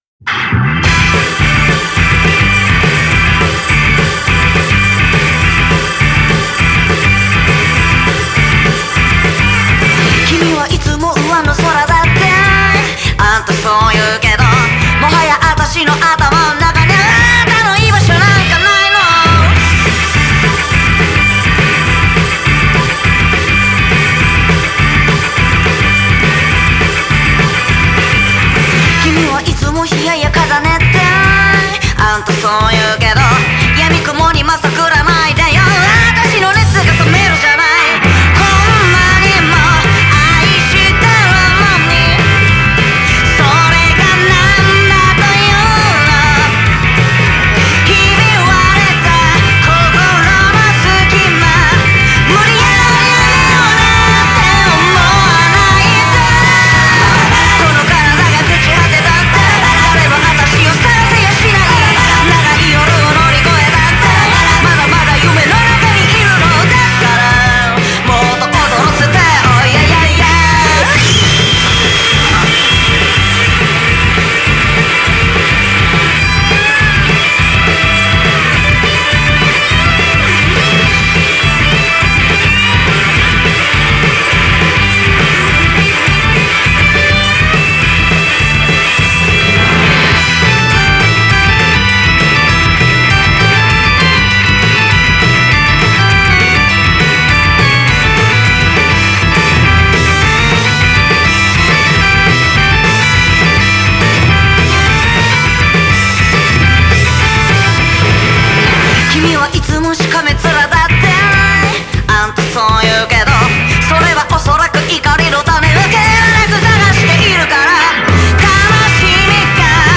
Genre: Psychedelic Rock, Garage